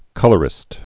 (kŭlər-ĭst)